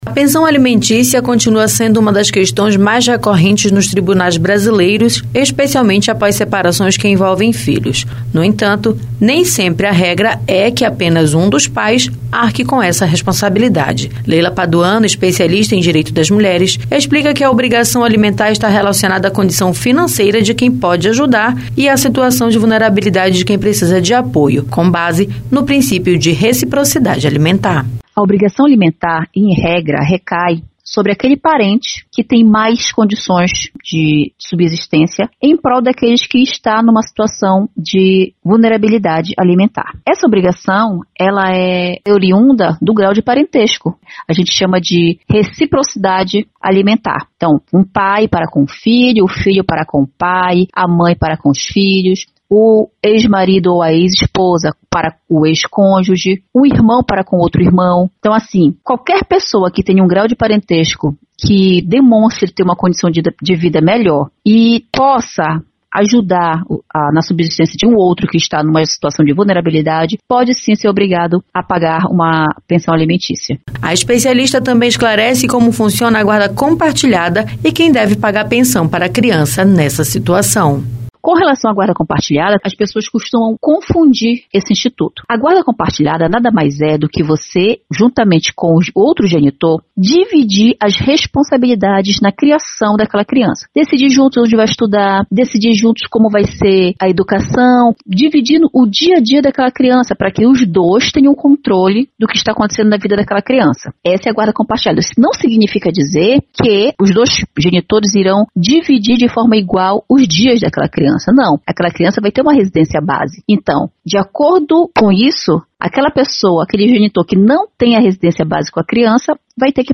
Especialista explica como funciona a reciprocidade alimentar